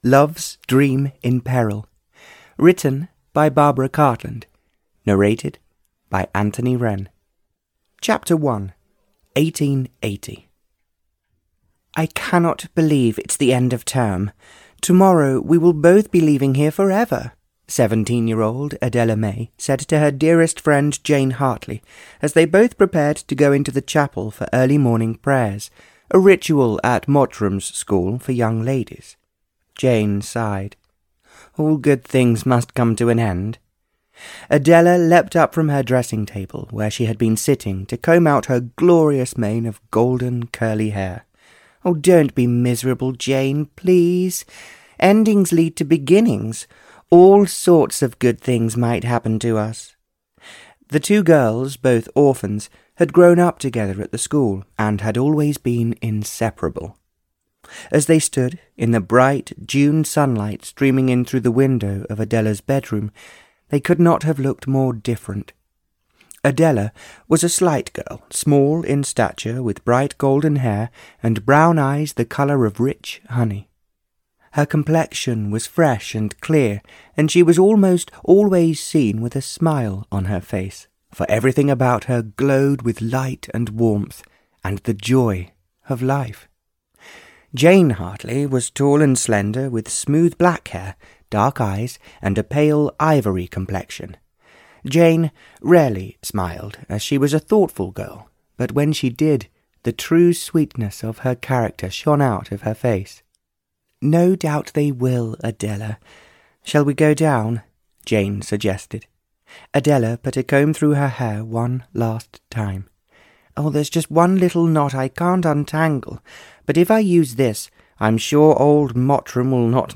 Love's Dream in Peril (EN) audiokniha
Ukázka z knihy